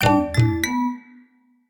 06829 tabla up completed
alert complete ding mallet multimedia ready sfx sound sound effect free sound royalty free Sound Effects